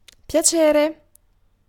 Ääntäminen
IPA : /dəˈlaɪt/